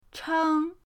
cheng1.mp3